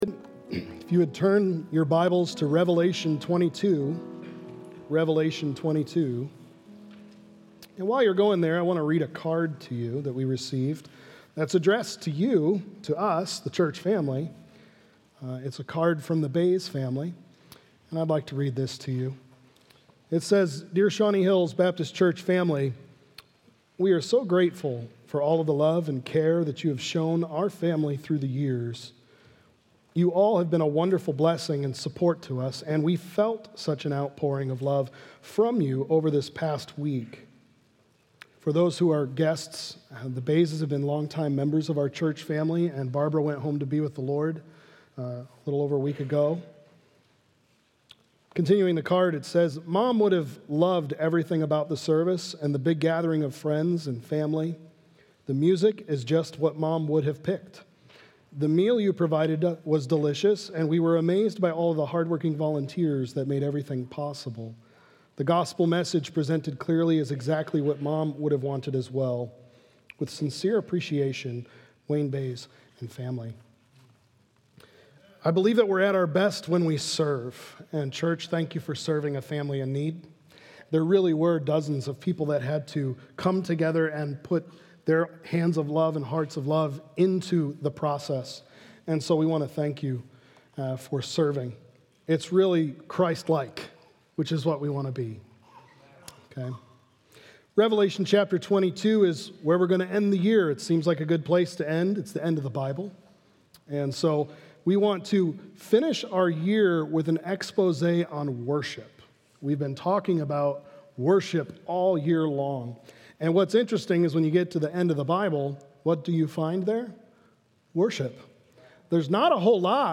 The End Of The Beginning of Worship | Baptist Church in Jamestown, Ohio, dedicated to a spirit of unity, prayer, and spiritual growth